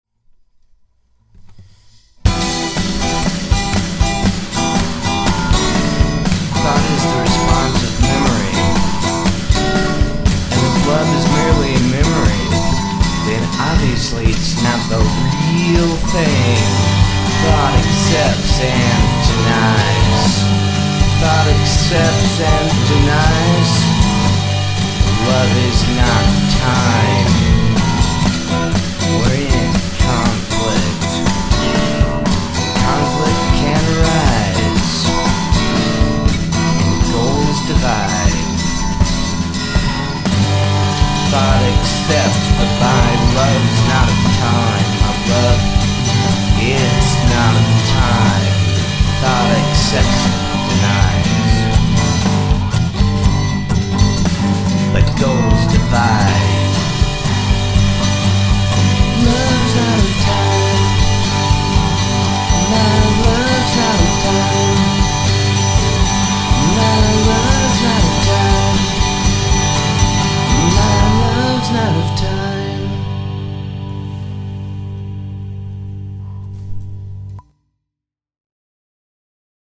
Experiment in Mind Control (music and poetry) audio
Manic Beatnik Riffing